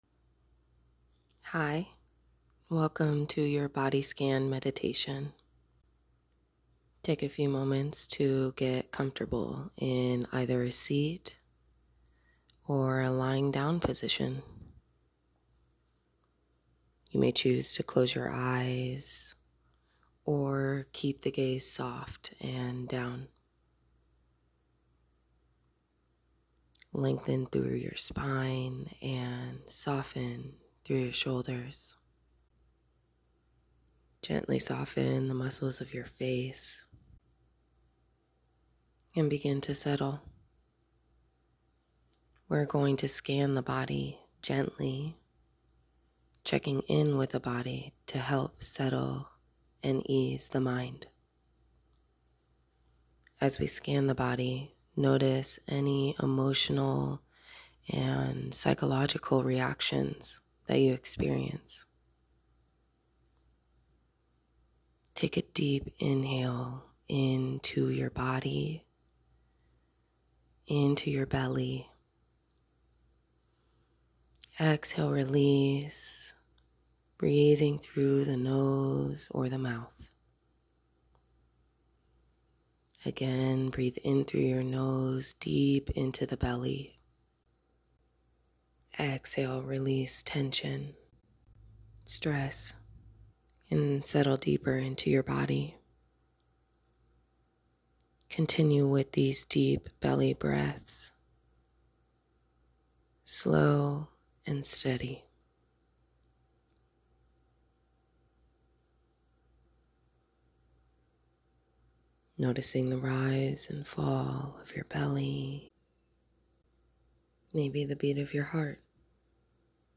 Simple. Grounding. Designed to help you come back into your body without needing to “do” anything else.
Body Scan MeditationListen Here
bodyscanmeditation-1.m4a